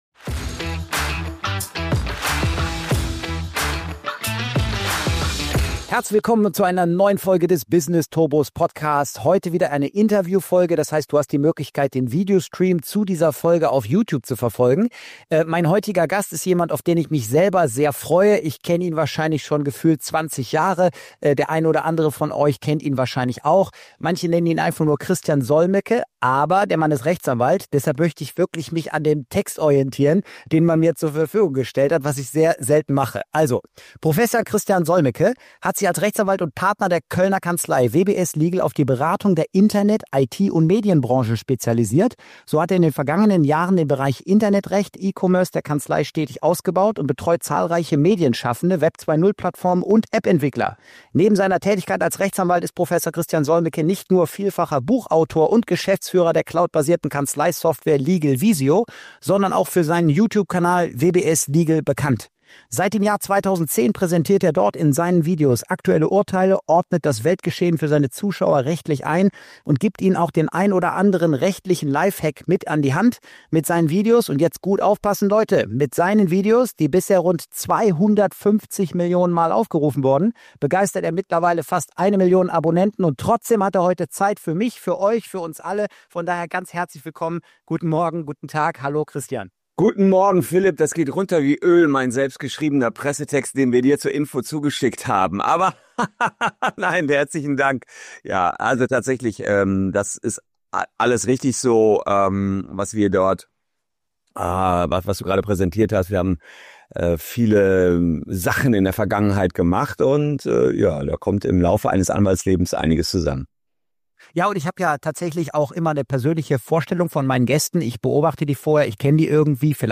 #115 - INTERVIEW mit CHRISTIAN SOLMECKE: Warum viele Unternehmer den falschen Anwalt wählen ~ Business Turbos Podcast
Beschreibung vor 4 Tagen In dieser Interviewfolge spreche ich mit einem der bekanntesten Rechtsanwälte Deutschlands: Prof. Christian Solmecke.